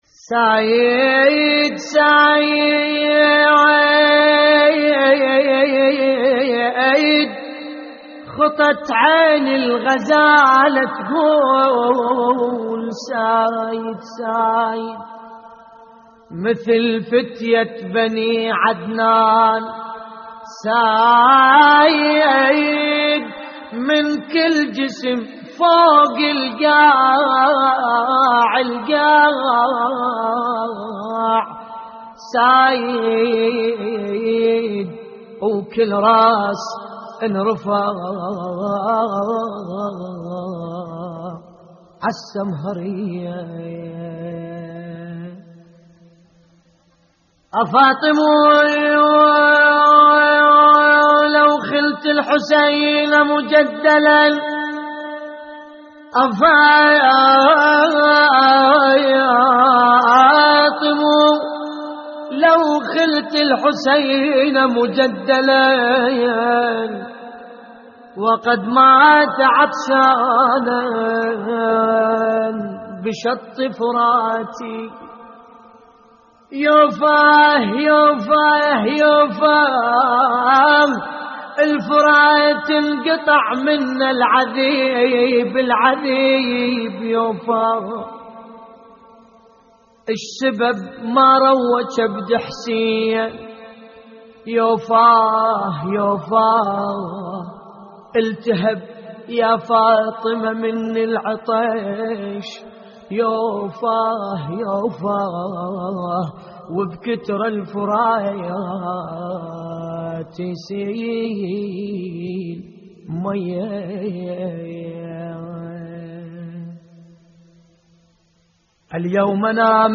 ابوذيات لحفظ الملف في مجلد خاص اضغط بالزر الأيمن هنا ثم اختر